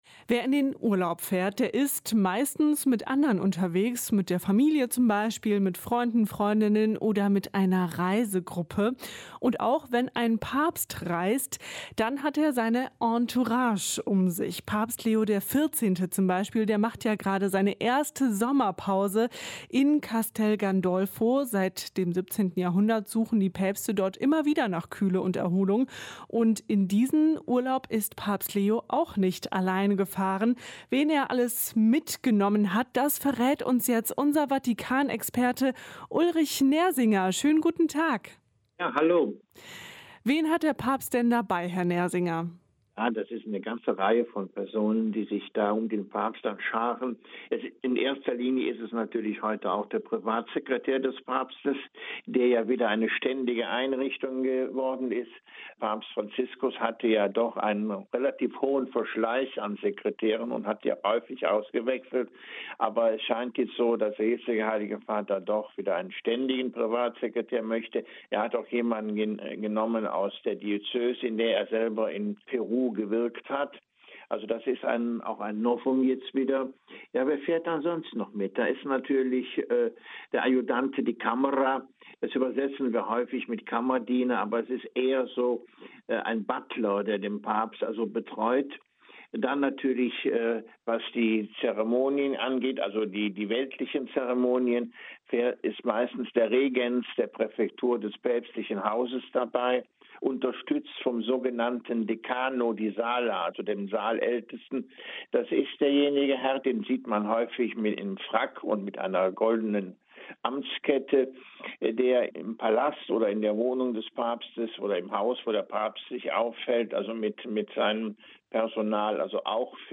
Ein Interview mit